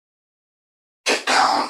Techno / Voice / VOICEFX175_TEKNO_140_X_SC2.wav